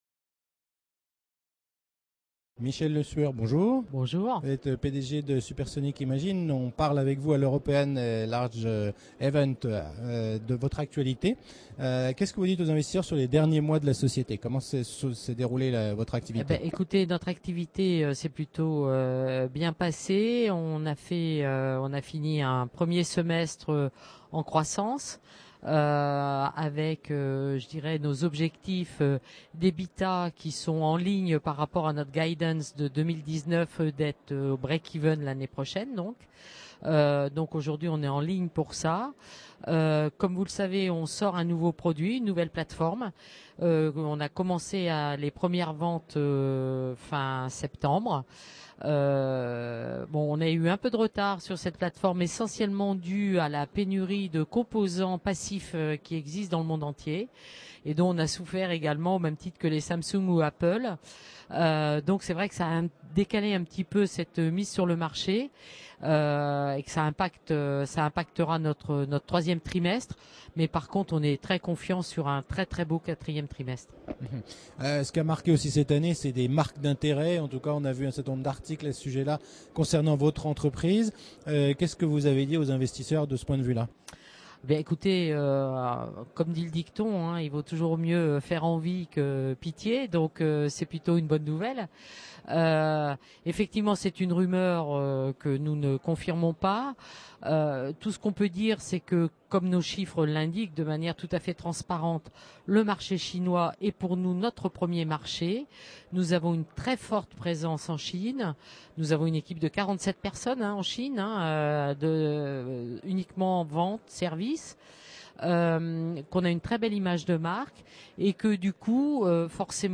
La Web Tv rencontre les dirigeants au Paris - European Large et Midcap Event